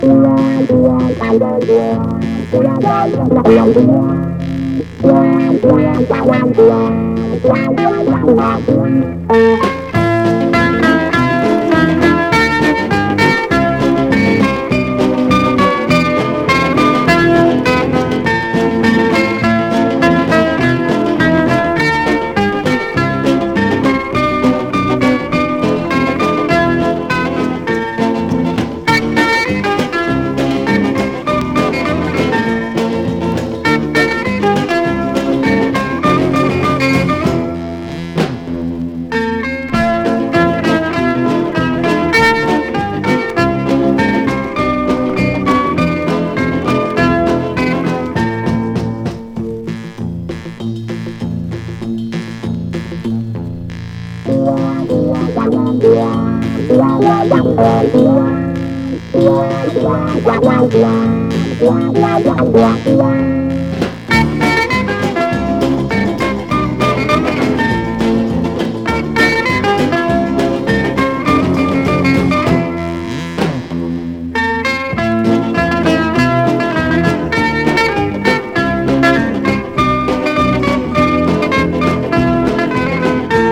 EASY LISTENING / EASY LISTENING / LOUNGE
ヴァイブやチェンバロでキラキラ感を出してカヴァーしているのが珍しくって魅力